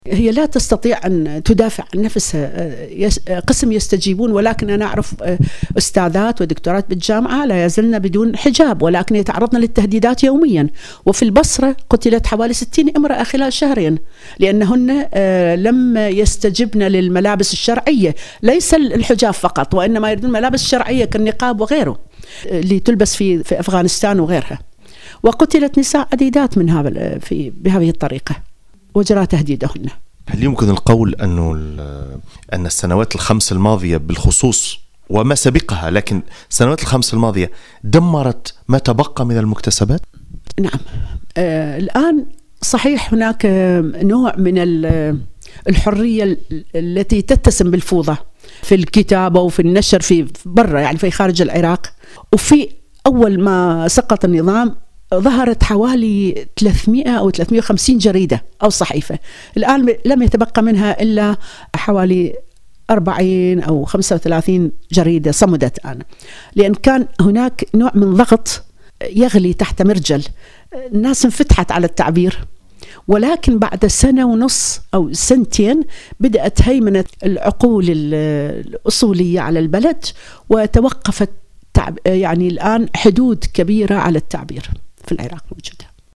الكاتبة والأديبة العراقية لُطفية الدّليمي في مُقتطف من حوار أجرته معها سويس انفو في برن يوم 18 أبريل 2008